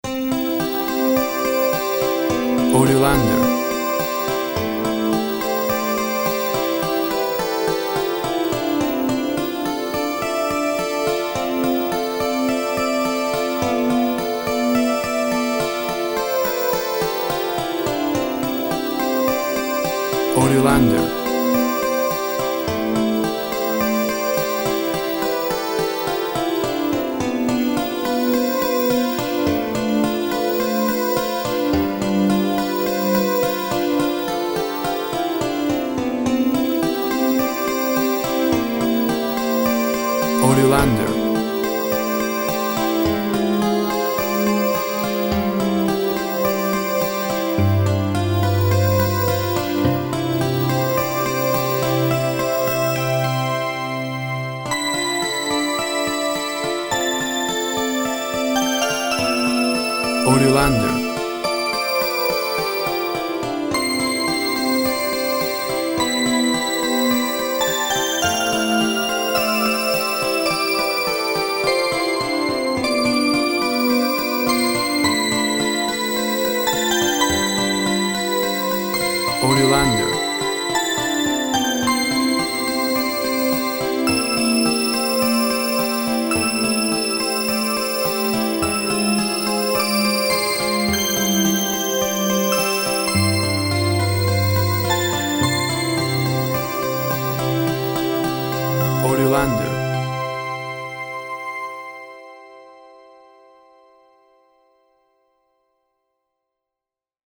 Sparkly dulcimer and synth.
Tempo (BPM) 104